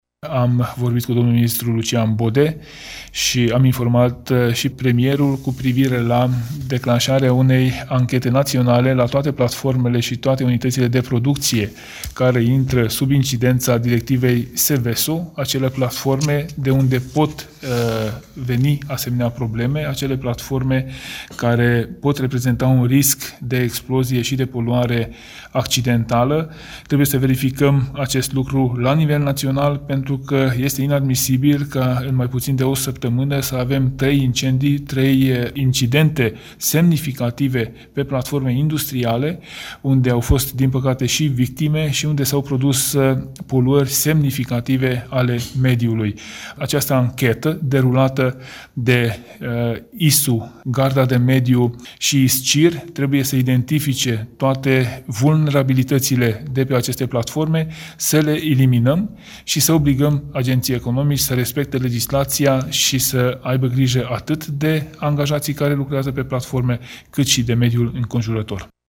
Ministrul Mediului Tanczos Barna: